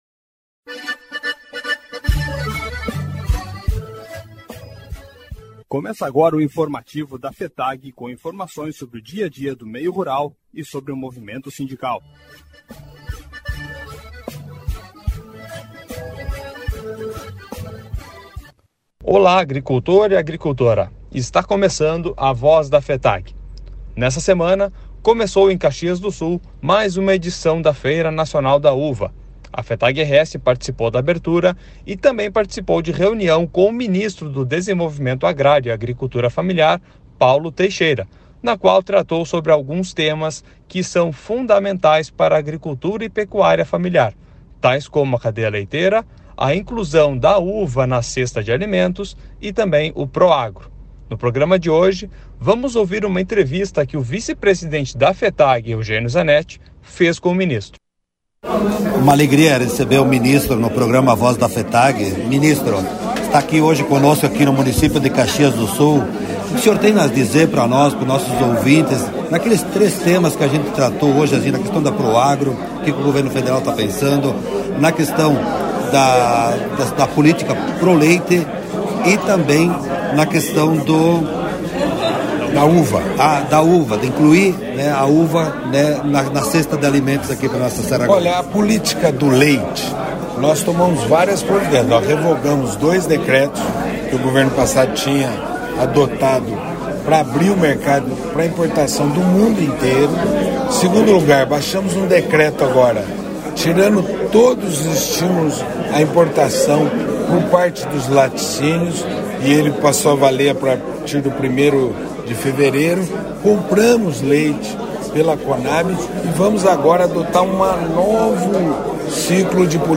A Voz da Fetag-RS – Entrevista com o Ministro do Desenvolvimento Agrário e Agricultura Familiar – Paulo Teixeira